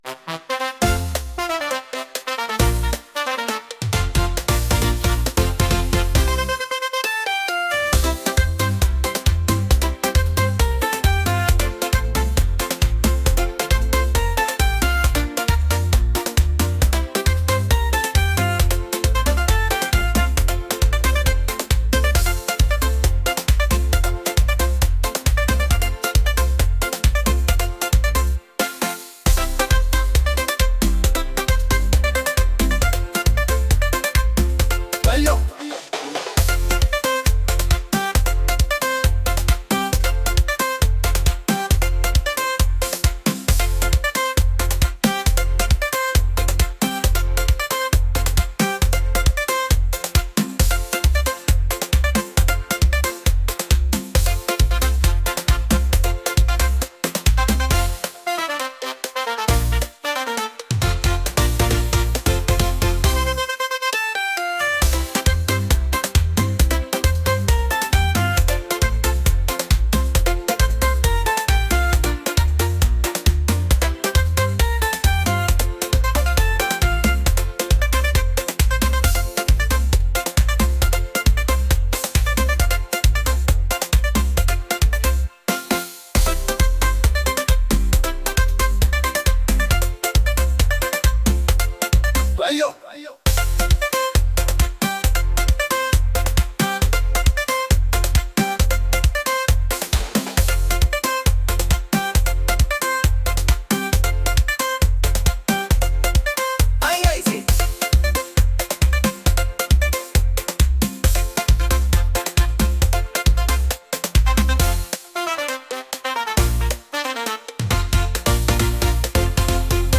energetic | upbeat